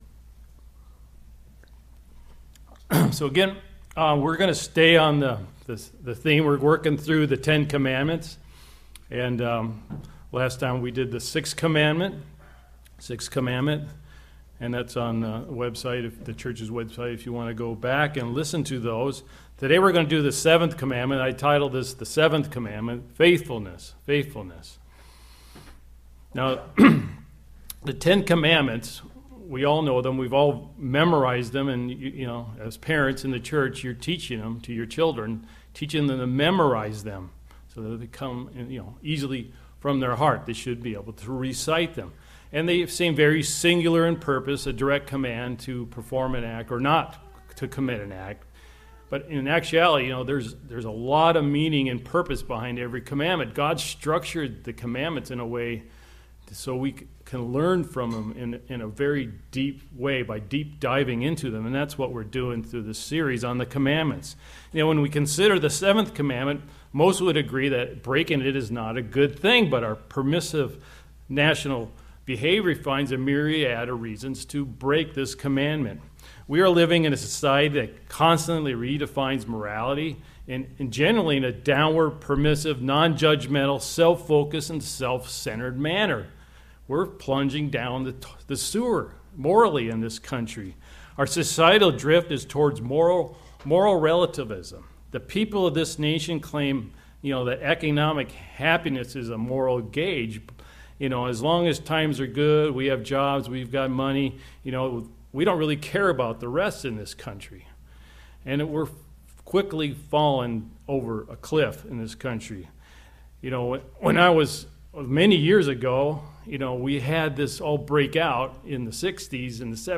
Sermons
Given in Kennewick, WA Chewelah, WA Spokane, WA